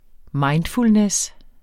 mindfulness substantiv, fælleskøn Udtale [ ˈmɑjndfulnεs ]